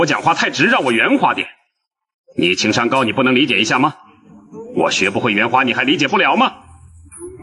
用于制作病毒式内容的社交媒体吐槽语音生成器
生成专为病毒式社交媒体评论和故事讲述而设计的高能量、有主见且富有共鸣的画外音。
文本转语音
高能量
情感丰富
我们的 AI 模仿人类发泄时的自发性，融合了自然的停顿、吸气和动态的音高变化，以传达真实的情感。